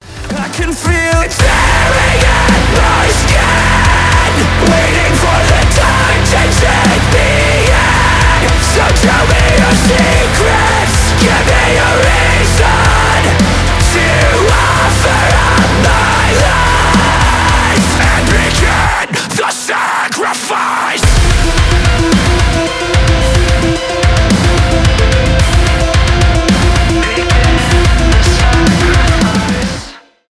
громкие , metalcore
мощные